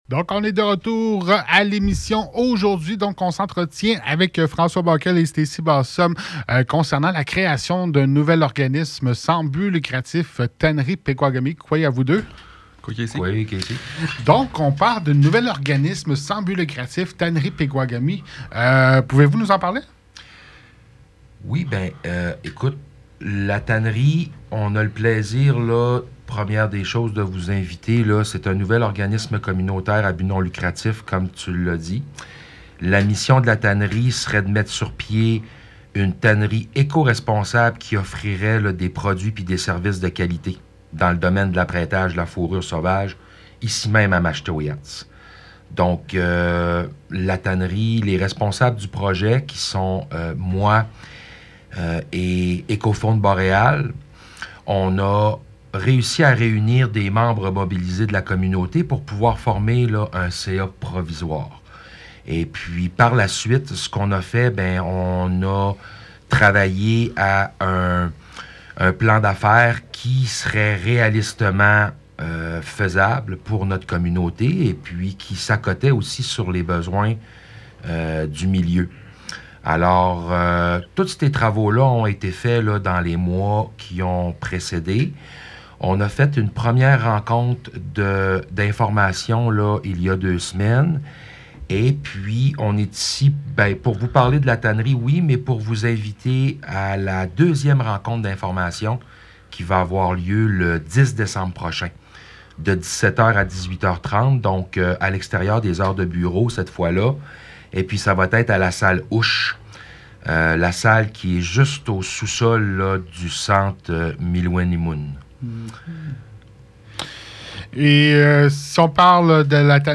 Écoutez l’entrevue Une seconde rencontre d’information sur le projet aura lieu ce mardi 10 décembre 2024 à 17 h à 18 h 30 à la Salle Ush (sous-sol du centre Miluelimun).